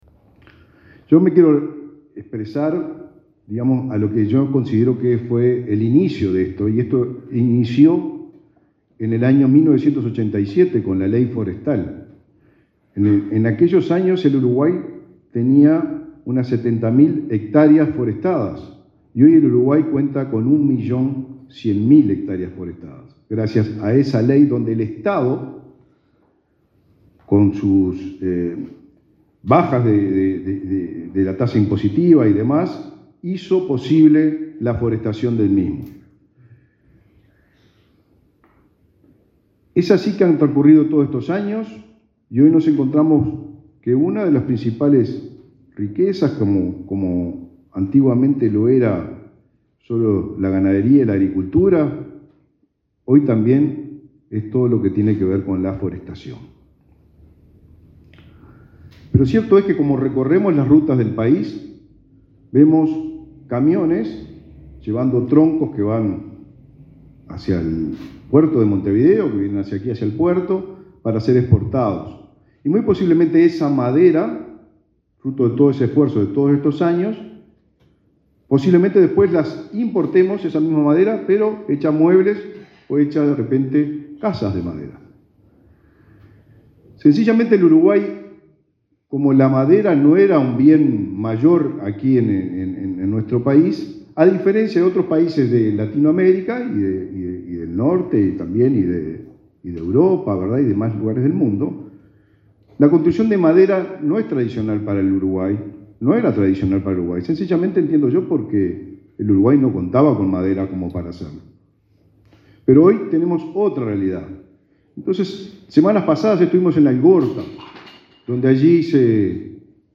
Palabras del ministro de Vivienda, Raúl Lozano
Palabras del ministro de Vivienda, Raúl Lozano 04/08/2023 Compartir Facebook X Copiar enlace WhatsApp LinkedIn El ministro de Vivienda, Raúl Lozano, participó en el acto en el que se anunciaron los resultados del llamado internacional para la construcción de viviendas de madera.